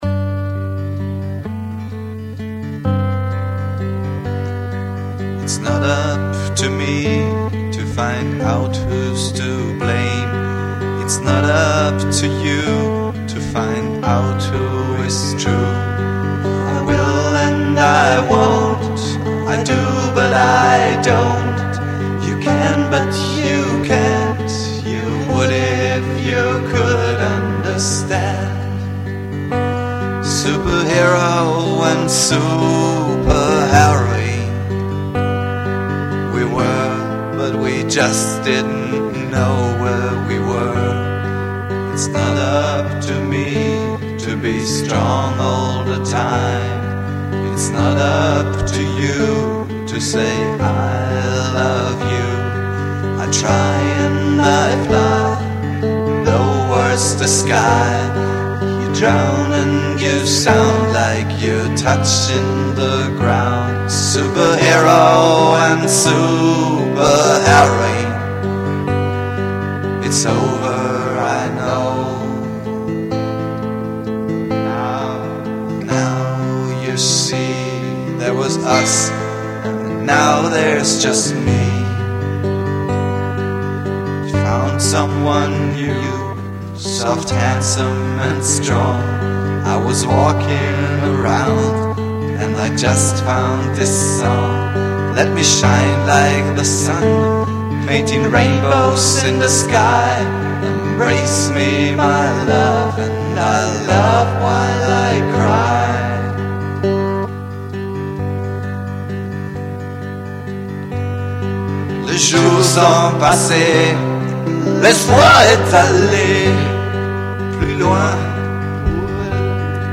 Genre: Freie Musik - Acoustic, Folk